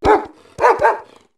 dachsbun_ambient.ogg